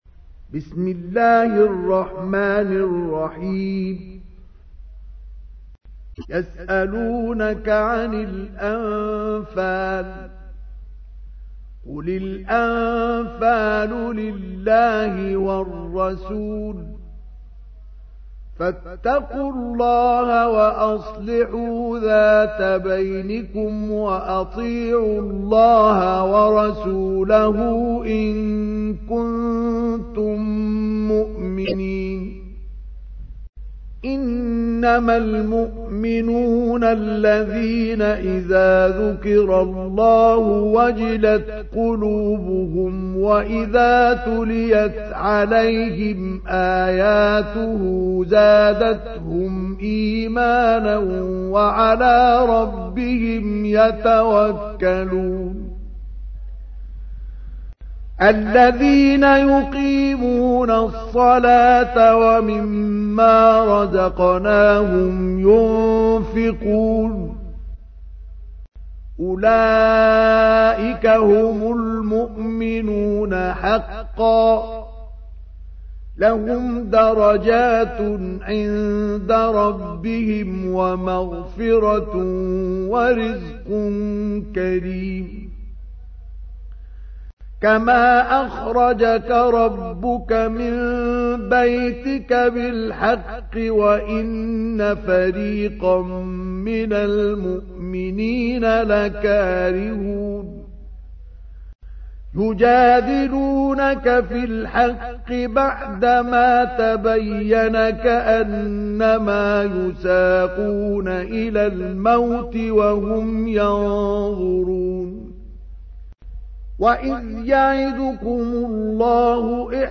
تحميل : 8. سورة الأنفال / القارئ مصطفى اسماعيل / القرآن الكريم / موقع يا حسين